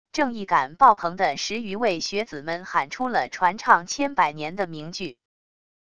正义感爆棚的十余位学子们喊出了传唱千百年的名句wav音频